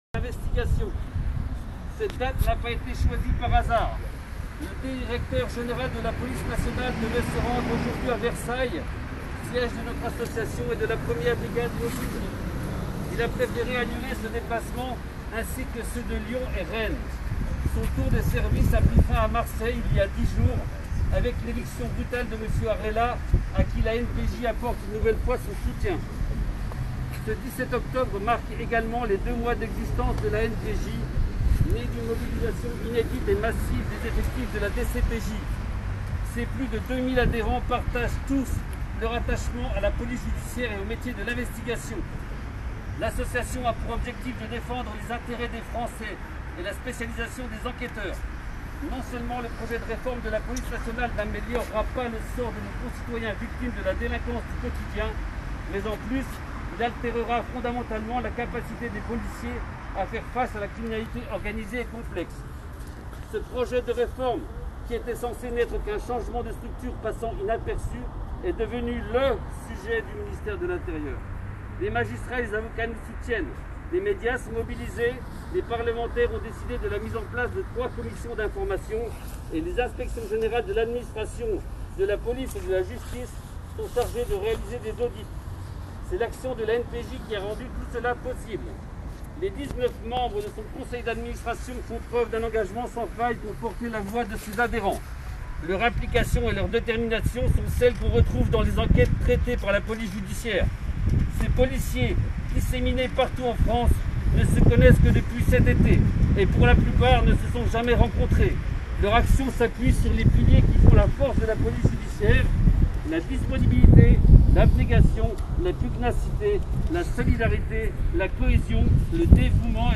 Le discours de l’ANPJ a été lu par des représentants devant la plupart des commissariats de France Photo : PF / Rue89 Strasbourg / cc
Dans un discours commun, lu par un représentant, l’ANPJ a tenu à rappeler le cadre de son action :